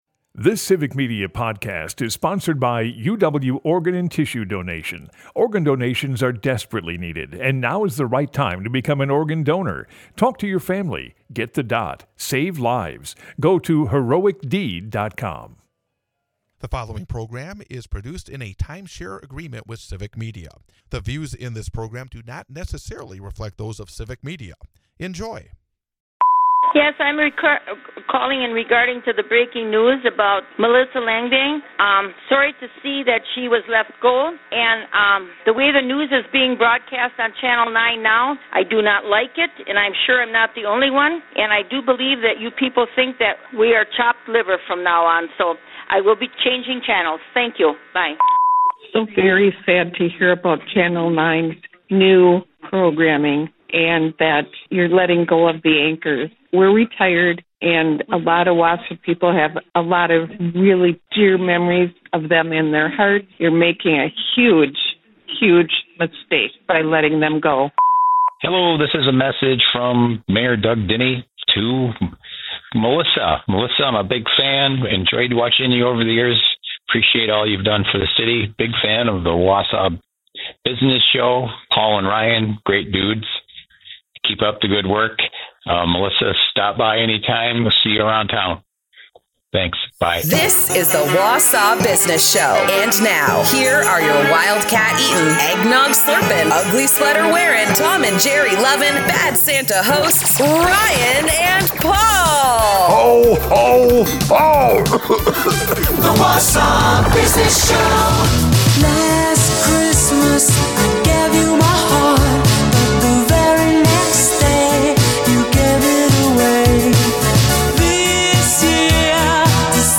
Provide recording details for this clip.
We hear your comments about the situation and more. The Wausau Business Show is a part of the Civic Media radio network and airs Saturday from 8-9 am on WXCO in Wausau, WI.